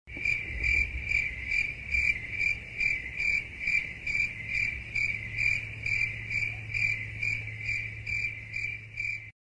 Download Cricket Insect sound effect for free.
Cricket Insect